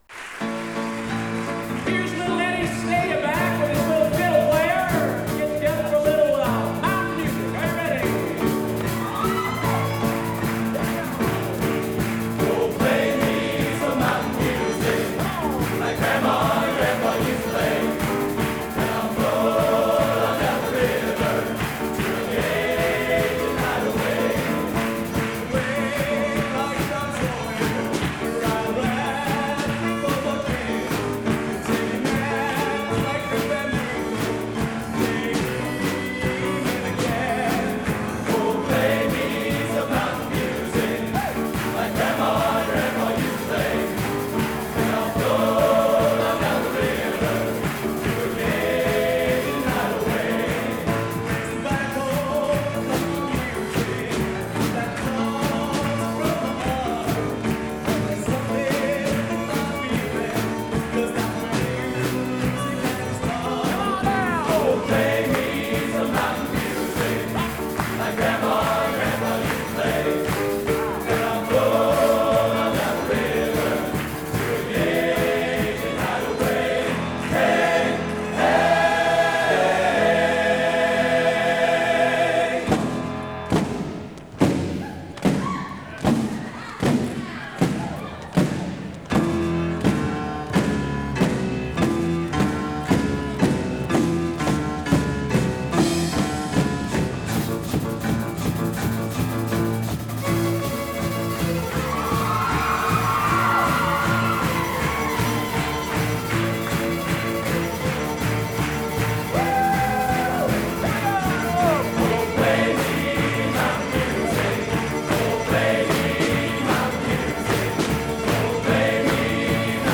Genre: Country/Western | Type: Solo